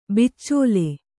♪ biccōle